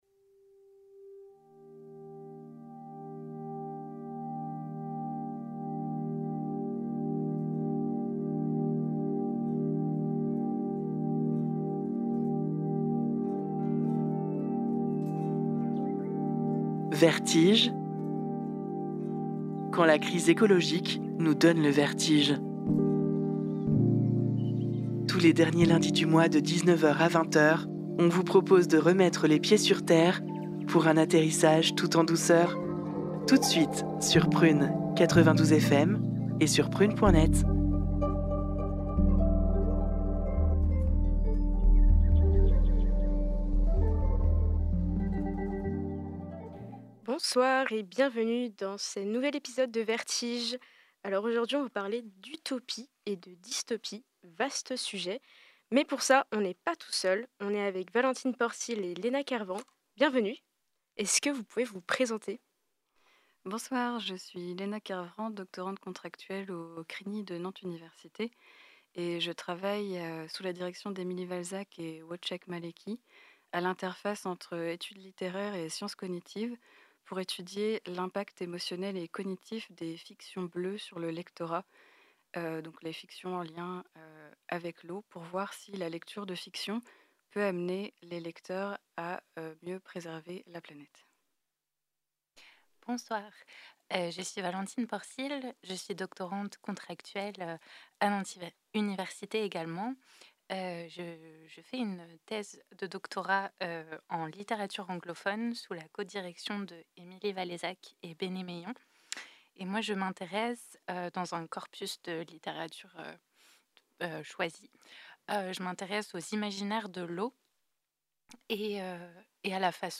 Extrait Film : Nausicaä de la Vallée du Vent de Hayao Miyazaki (1984)